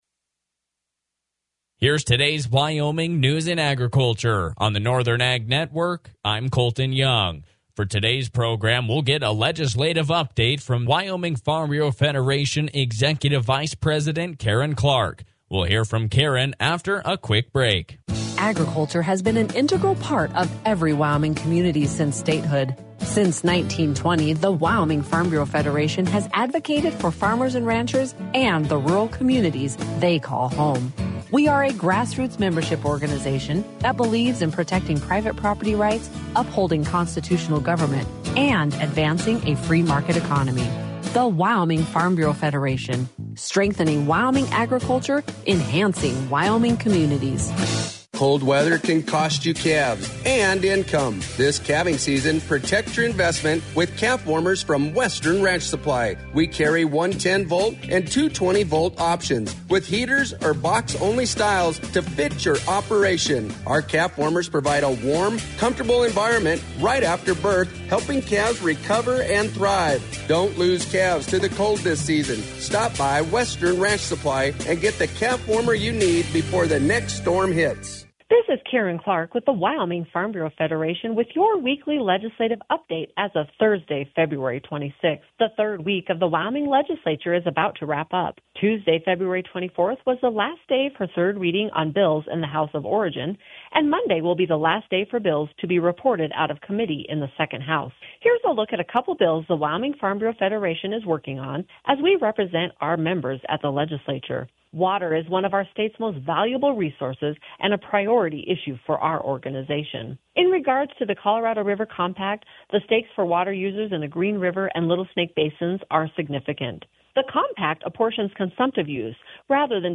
WyFB will be live on Northern Ag Network Radio every Thursday at 3:10!